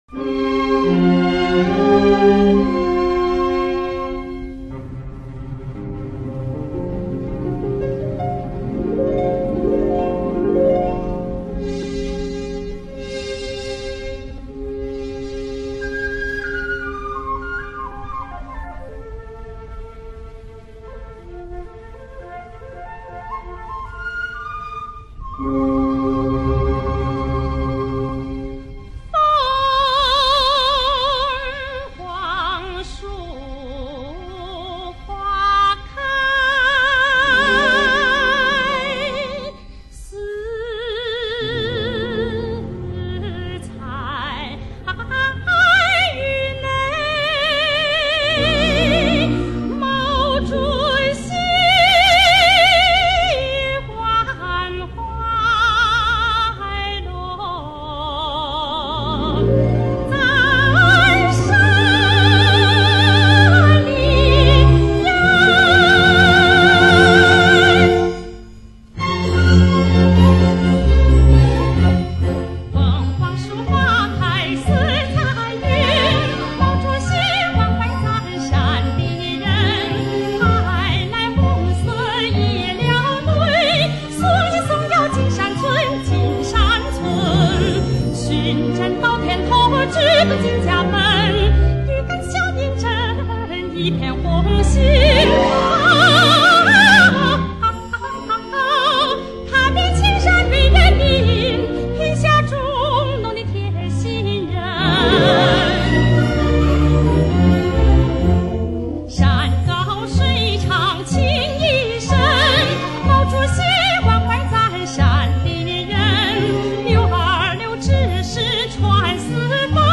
单声道